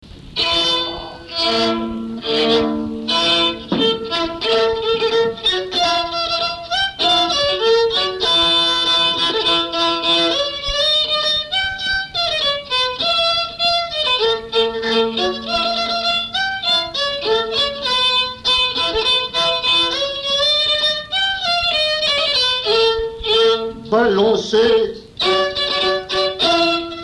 Mémoires et Patrimoines vivants - RaddO est une base de données d'archives iconographiques et sonores.
danse : quadrille : chaîne anglaise
Pièce musicale inédite